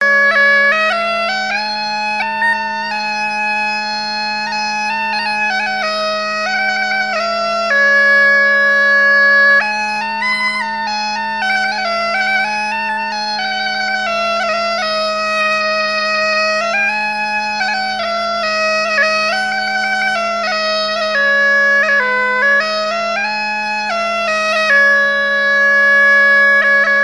Airs joués à la veuze et au violon
Pièce musicale inédite